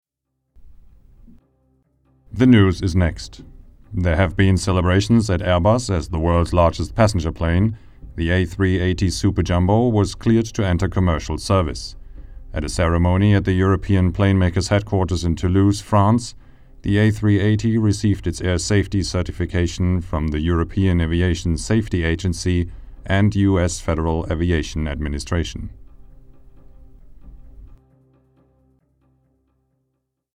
deutscher Sprecher
Sprechprobe: Industrie (Muttersprache):
german voice over talent